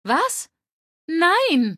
Datei:Femaleadult01default ms02 ms02retireno 000ac03f.ogg
Fallout 3: Audiodialoge